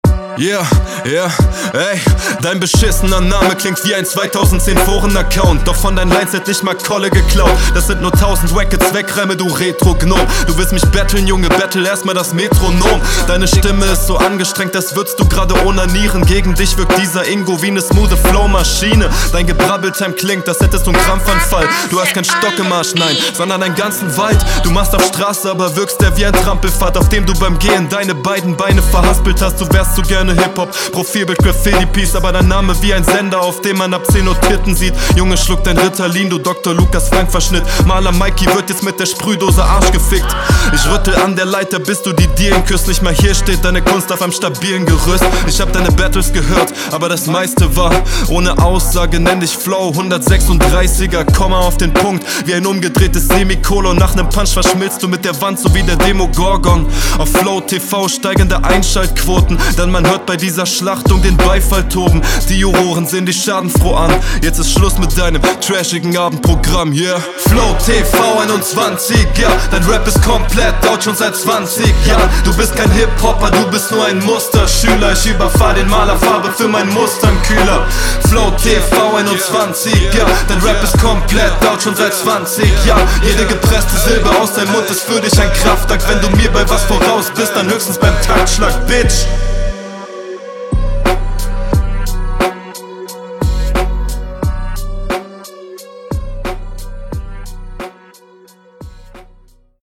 Flow und Stimmeinsatz sind baba darüber brauchen wir ja nicht reden.
Mische on fire und auch dein Flow gefällt mir sehr gut.
- nicer beat, gute mische - bisschen viel flache beleidungslines für meinen geschmack